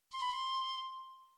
bonus-sound film-production flute flutter game-development intro magic magical-flute-sound sound effect free sound royalty free Movies & TV